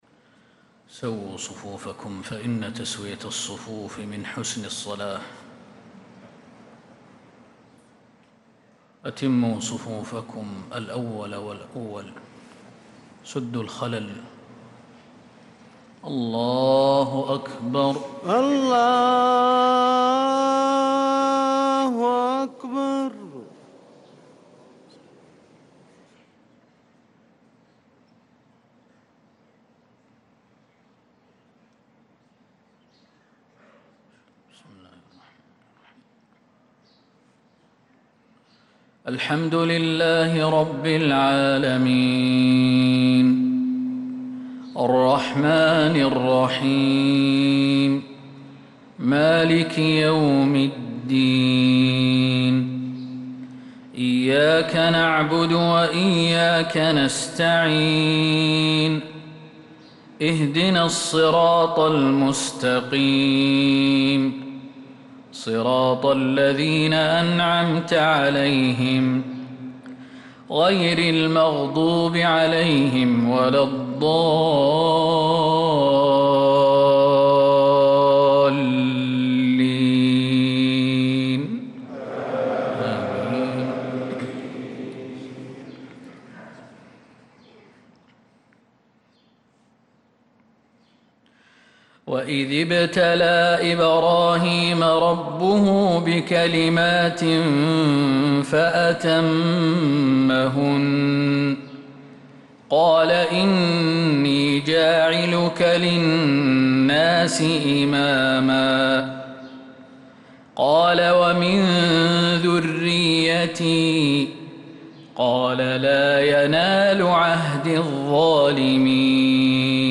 صلاة الفجر للقارئ خالد المهنا 4 ذو الحجة 1445 هـ
تِلَاوَات الْحَرَمَيْن .